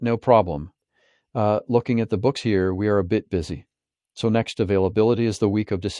Orpheus - English (US) - Male.wav